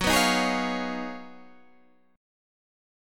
G#m13/F# Chord
Listen to G#m13/F# strummed